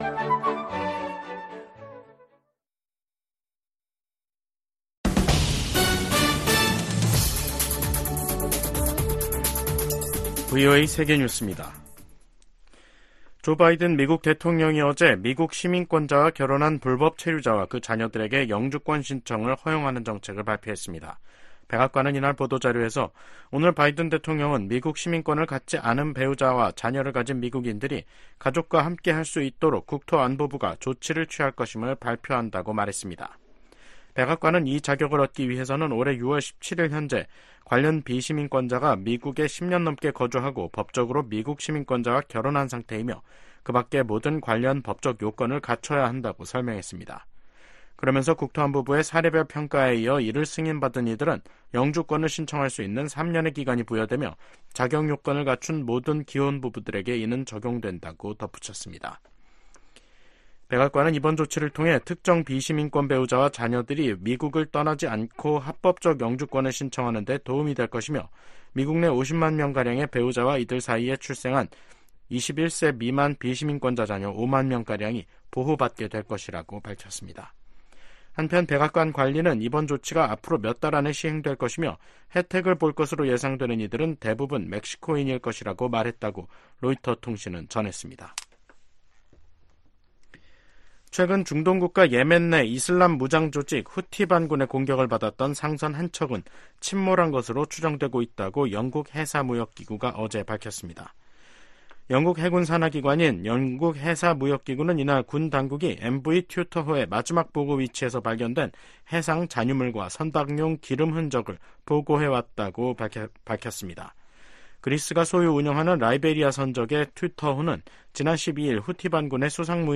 VOA 한국어 간판 뉴스 프로그램 '뉴스 투데이', 2024년 6월 19일 2부 방송입니다. 김정은 북한 국무위원장과 블라디미르 푸틴 러시아 대통령이 오늘, 19일 평양에서 정상회담을 갖고 포괄적 전략 동반자 협정에 서명했습니다. 미국 백악관은 푸틴 러시아 대통령이 김정은 북한 국무위원장에게 외교가 한반도 문제 해결의 유일한 해법이라는 메시지를 전달해야 한다고 촉구했습니다.